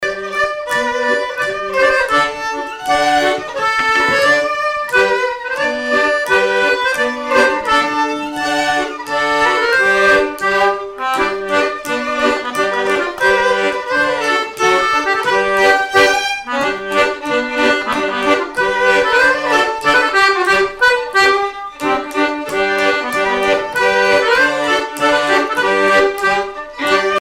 Miquelon-Langlade
danse : aéroplane
Répertoire de bal au violon et accordéon
Pièce musicale inédite